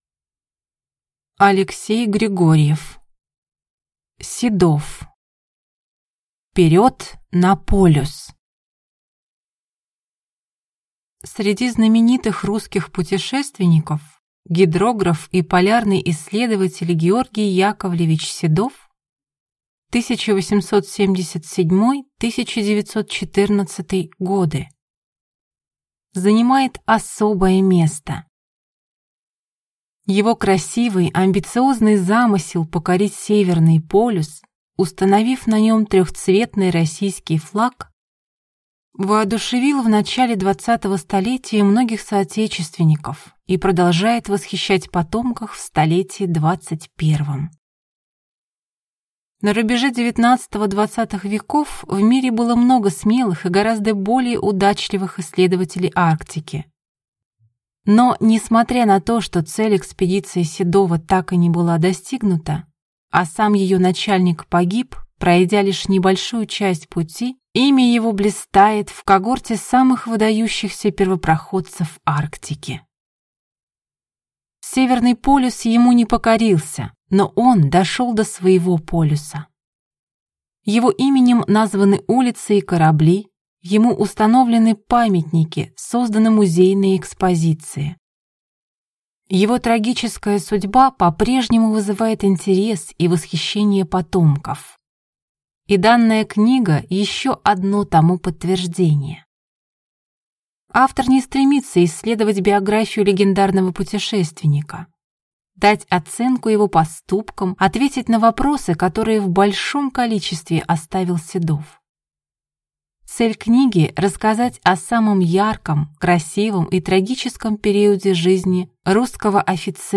Аудиокнига Седов. Вперёд на полюс!